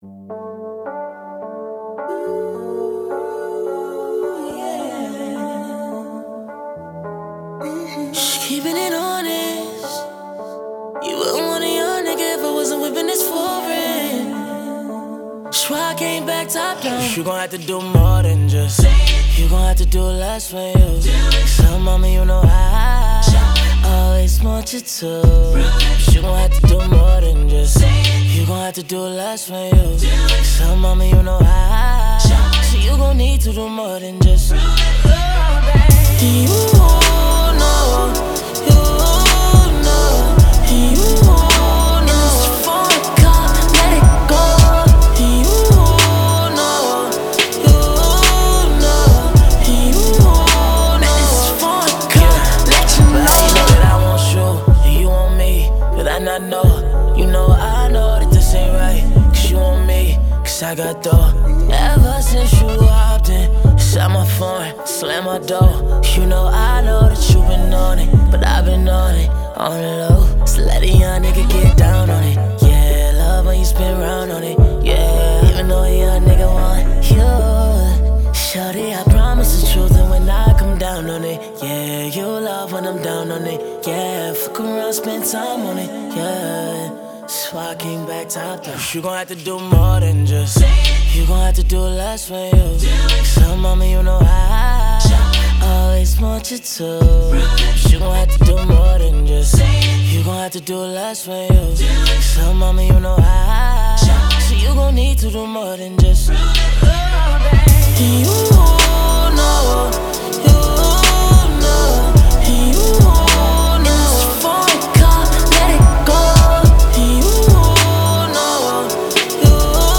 Genre: 10s-R&B.